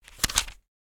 page-flip-18.ogg